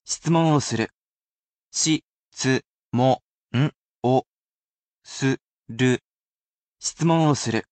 Listening (or Reading) Practice |日本語の聞き取り（若しくは読み取り）練習
We have here with us the portable version of our computer robot friend, QUIZBO™ Mini, who will be here to help read out the audio portions.